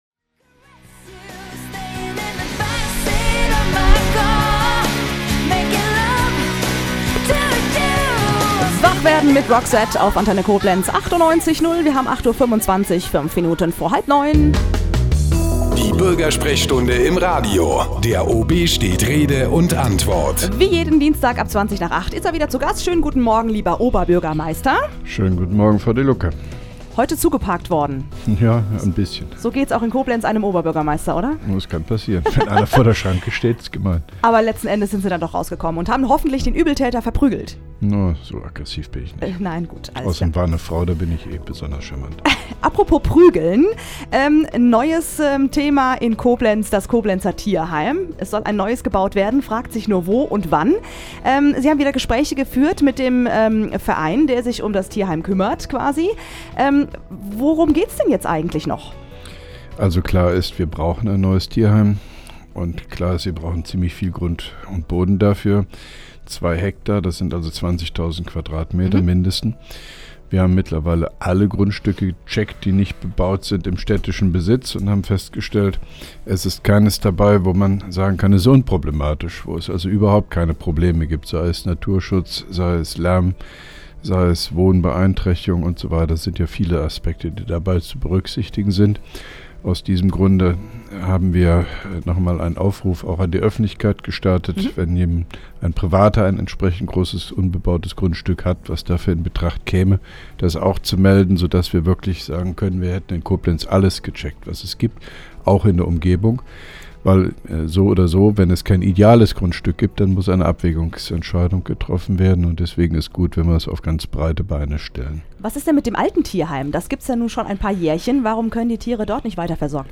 (1) Koblenzer Radio-Bürgersprechstunde mit OB Hofmann-Göttig 01.02.2011